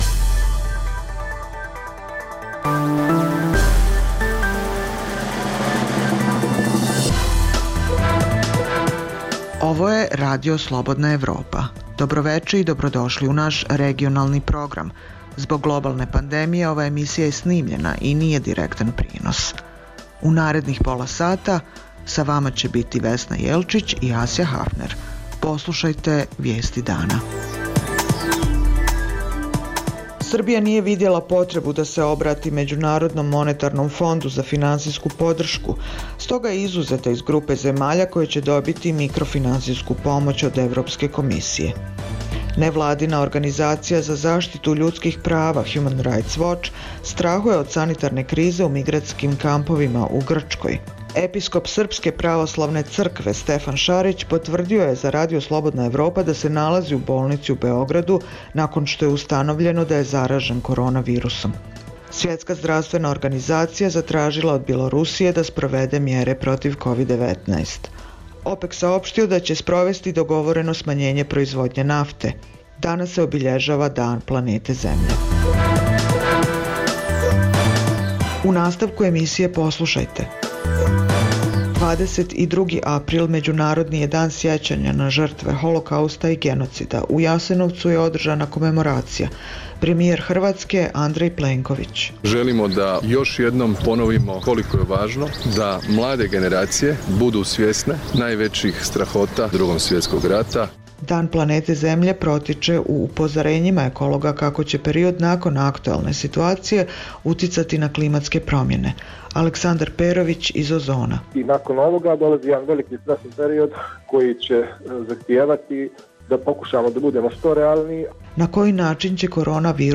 Zbog globalne pandemije, ova je emisija unapred snimljena i nije direktan prenos. Srbija nije videla potrebu da se obrati Međunarodnom Monetarnom Fondu za financijsku podršku, stoga je izuzeta iz grupe zemalja koje će dobiti mikrofinancijsku pomoć od Evropske komisije (EK). Nevladina organizacija za zaštitu ljudskih prava Hjuman rajts voč (HRW) strahuje od sanitarne krize u migrantskim kampovima u Grčkoj.